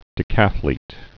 (dĭ-kăthlēt)